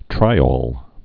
(trīôl, -ōl, -ŏl)